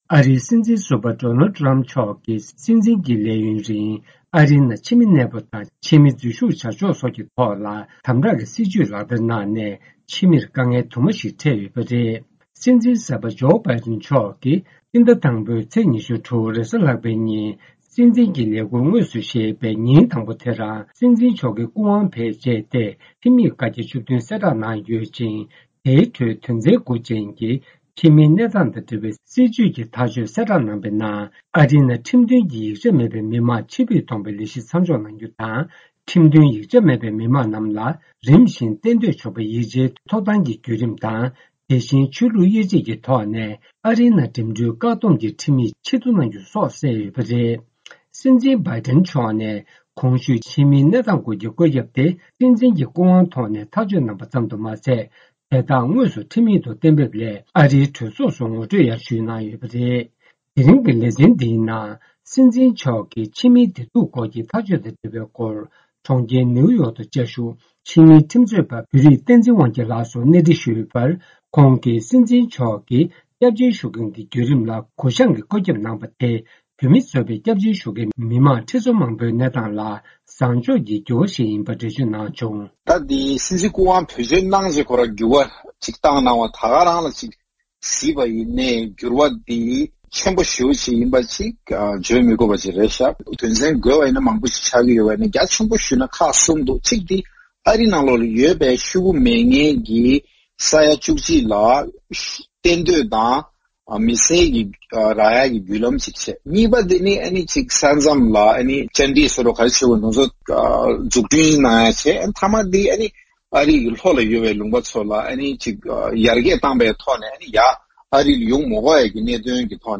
བཅར་འདྲི་ཕྱོགས་བསྒྲིགས་ཞུས་པ་ཞིག་གསན་རོགས་ཞུ།།